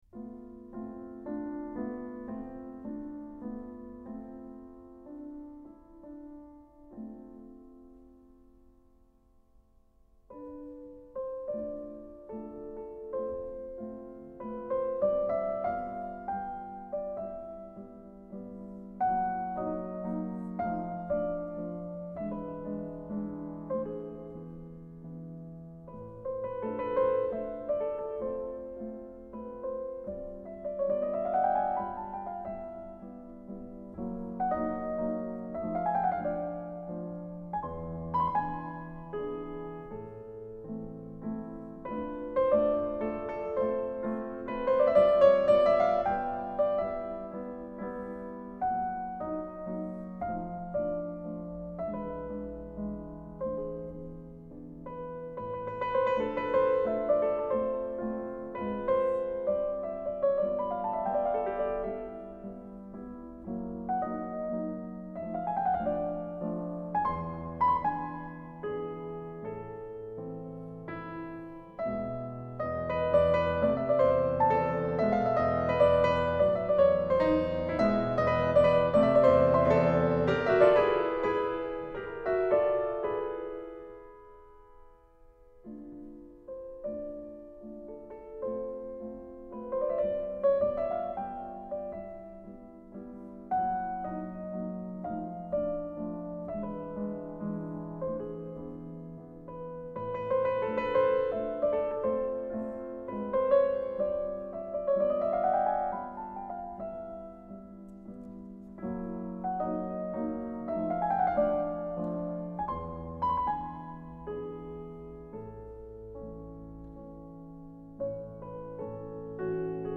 Vladimir Ashkenazy 1985 – Frédéric Chopin mazurka en la mineur Opus 17 n°4